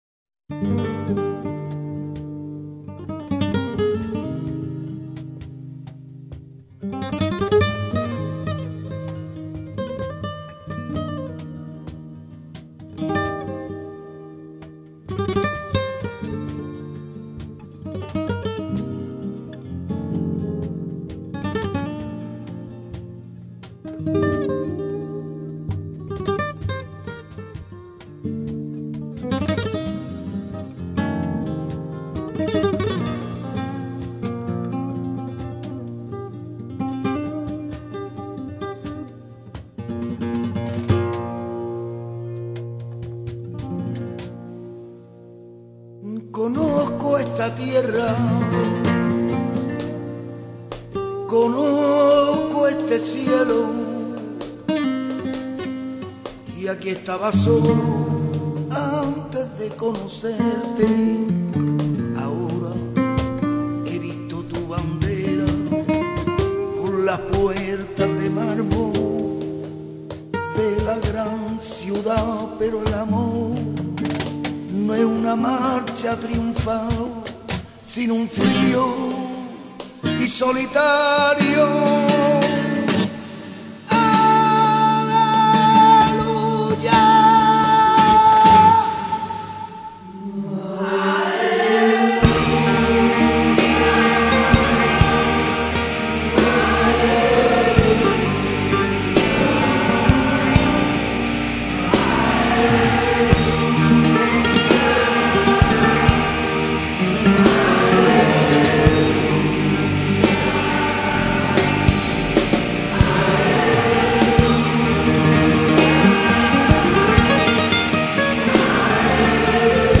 flamenco